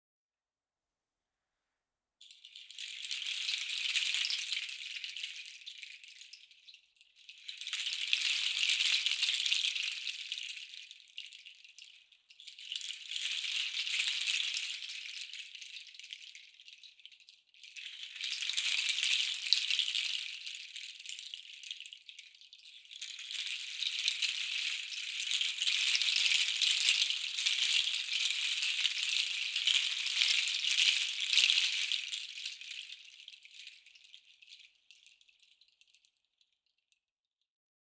Bendo seed nutshells, attached to a solid piece of mahogany, create a unique, versatile instrument. The Meinl Sonic Energy Bendo Chimes are played like typical chimes but offer a completely different sound variety. Its clear and crisp sound stands out from the masses and is perfect for relaxing sound baths and musical sessions.
Feature 1 Clear, crisp sound
Material Bendo Seed Nutshells/Mahogany wood
MEINL Sonic Energy Bendo Chimes sample
meinl sonic energy bendo chimes sample.mp3